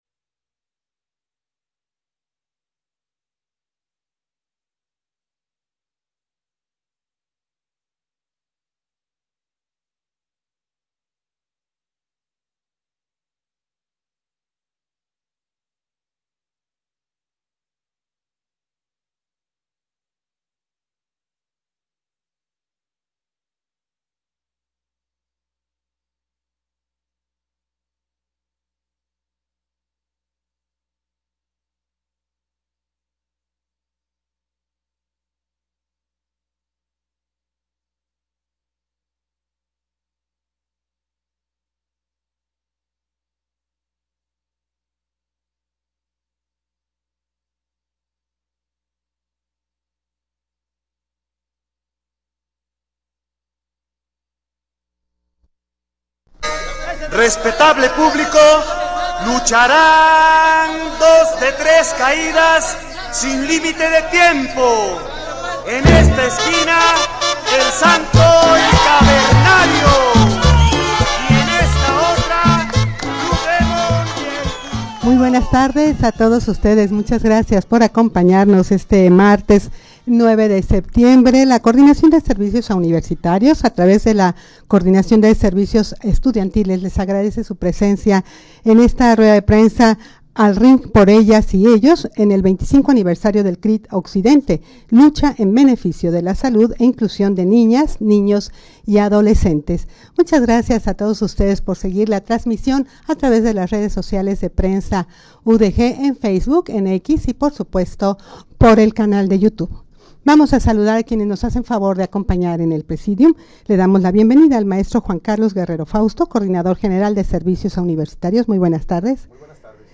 Audio de la Rueda de Prensa
rueda-de-prensa-al-ring-por-ellas-y-ellos-25-aniversario-del-crit-occidente.mp3